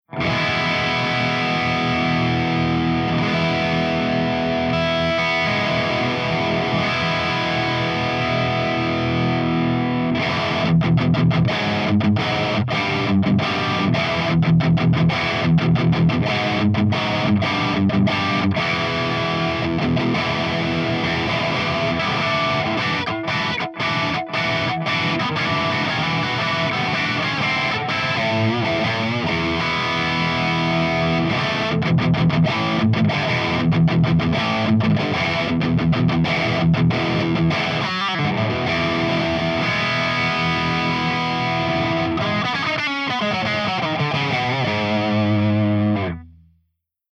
161_EVH5150_CH2HIGHGAIN_V30_P90